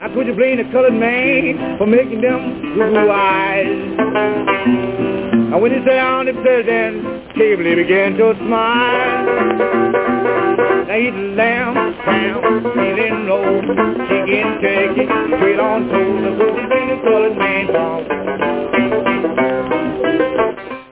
вокал, банджо
гитара